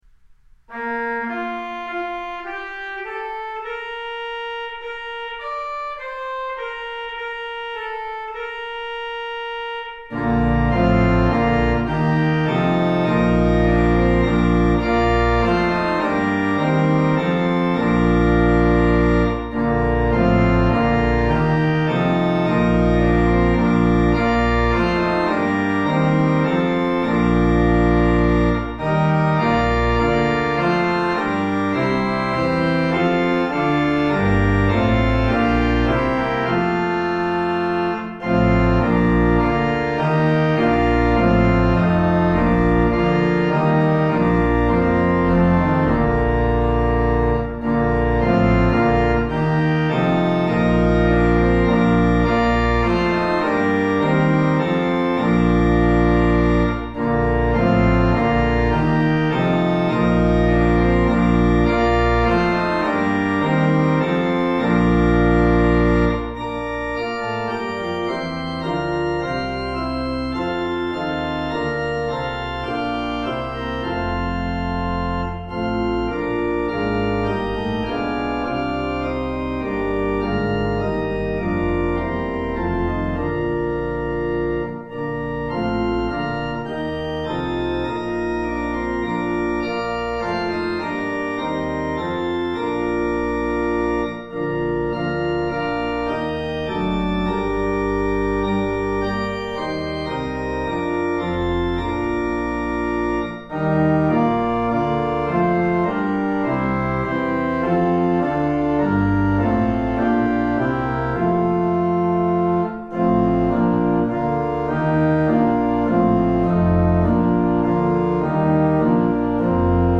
Organ: Moseley